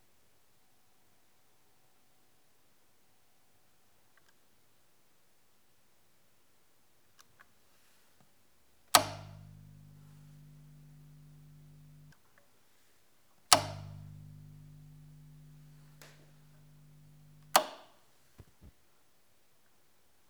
Klack 2
Das ist ein Schalter des Sansui AU-777.
Dieser Schalter schaltet wirklich etwas um, mit der ganzen Macht seiner sorgfältig konstruierten Mechanik.
klack-2.wav